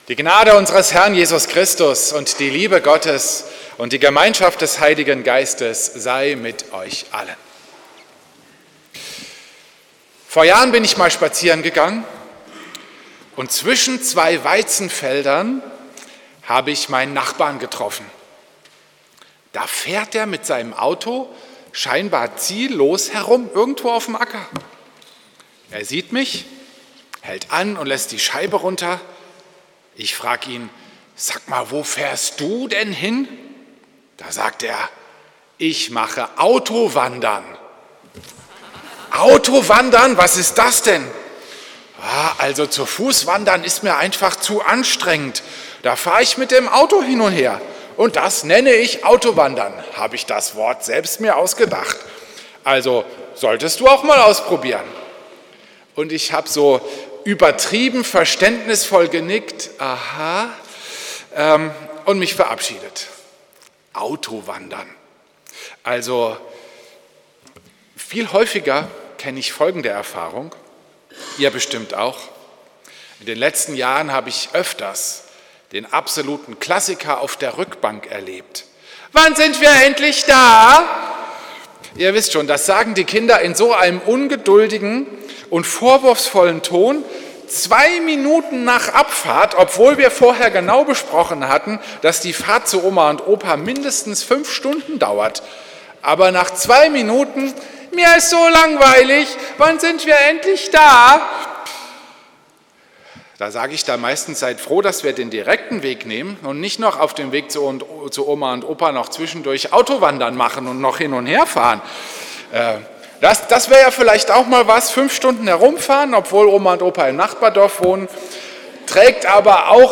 Predigt am Sonntag Invokavit